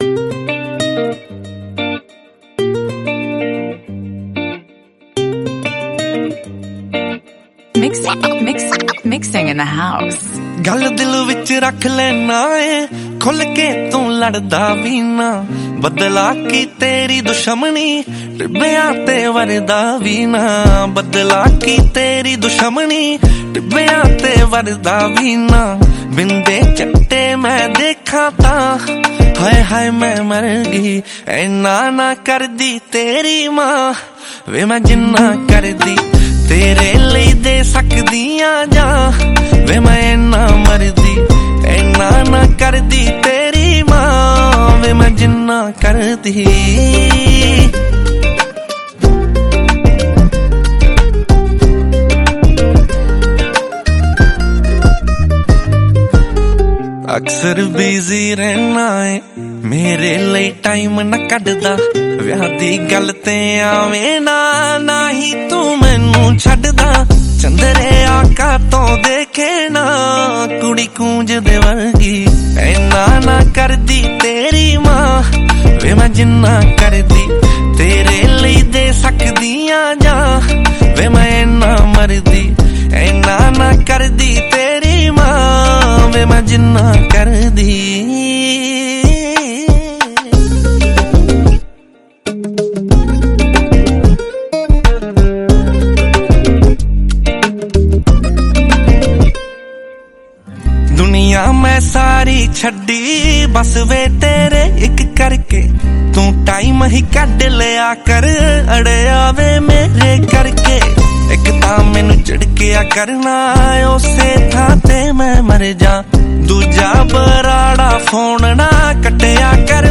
Song Genre : Punjabi Songs